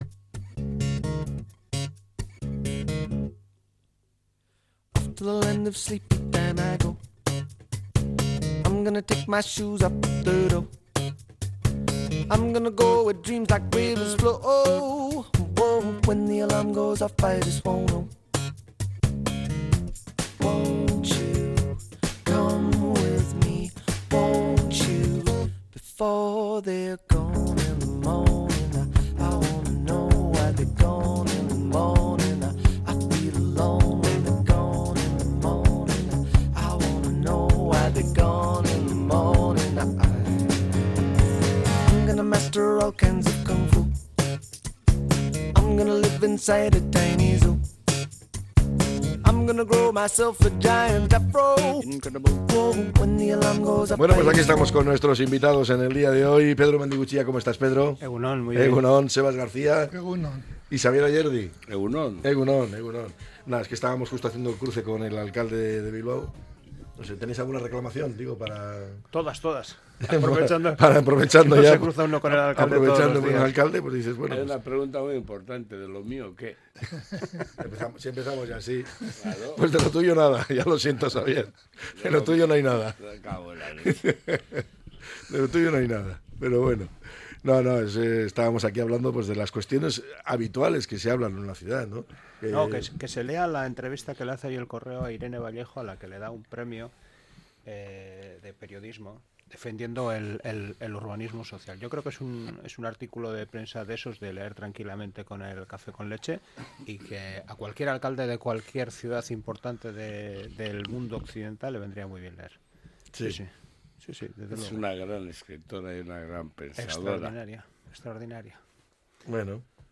La tertulia 28-02-25.